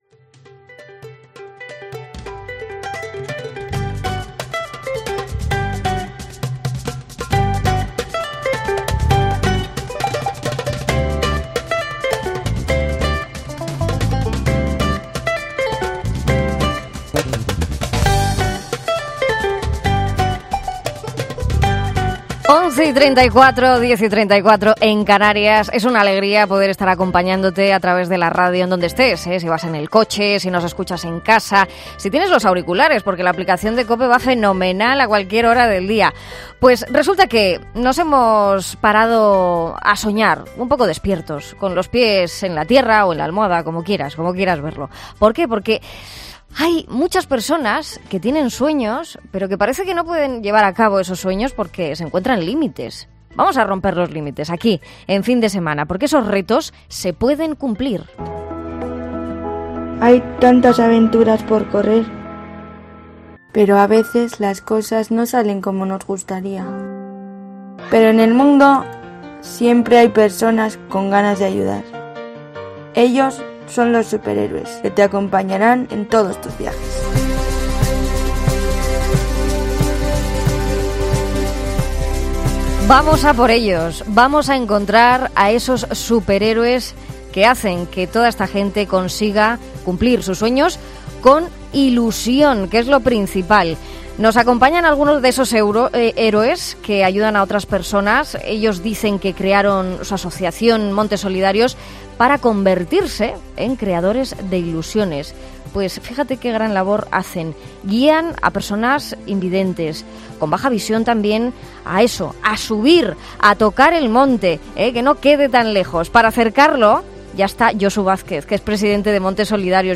En Fin de Semana COPE también hemos hablado con varios miembros de la Asociación “Montes Solidarios”, que guían a personas ciegas y con baja visión a cumplir su sueño de andar por la montaña.